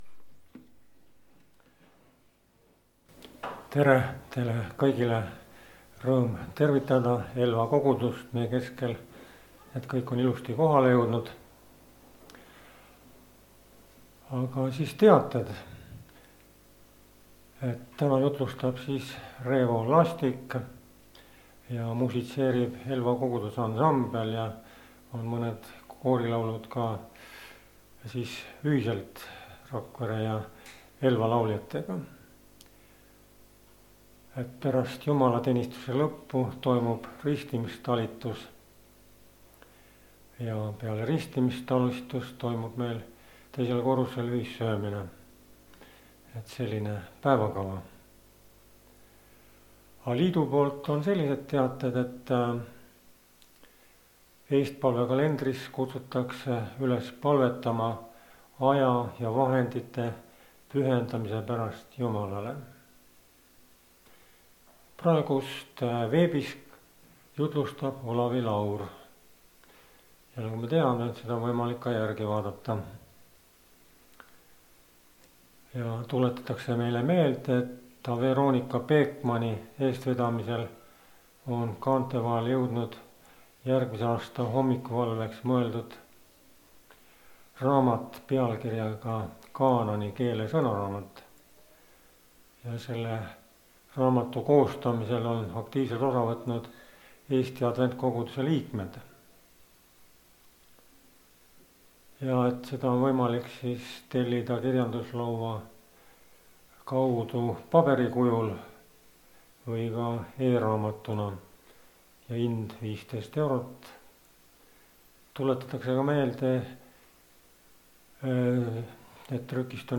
Koosolekute helisalvestused